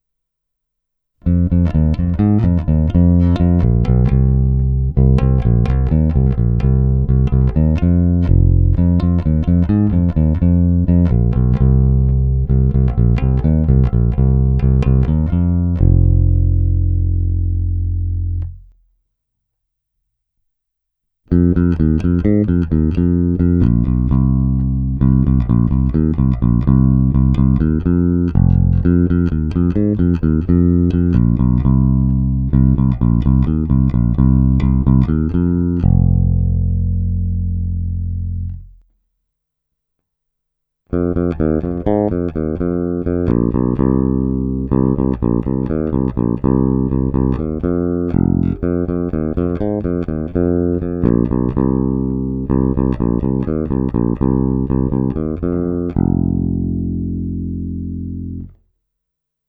Není-li uvedeno jinak, následující ukázky jsou provedeny rovnou do zvukové karty a jen normalizovány.